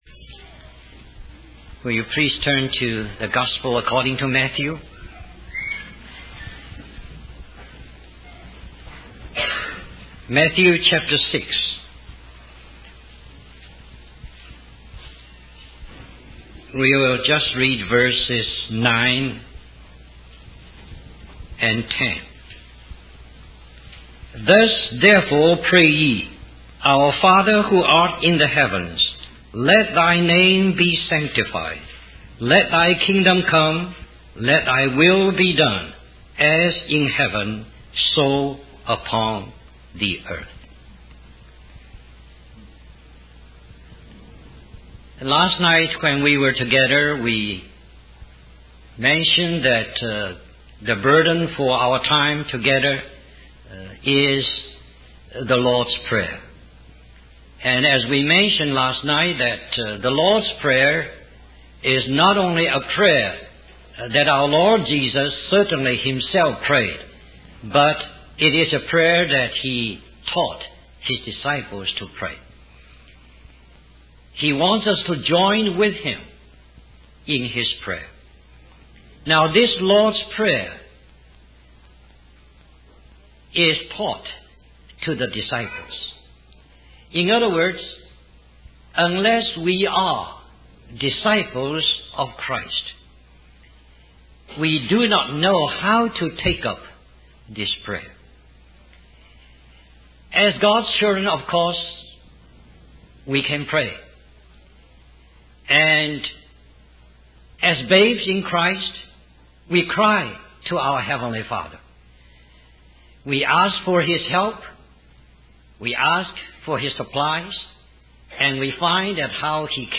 In this sermon, the preacher emphasizes the importance of sanctifying God and accepting what He has done.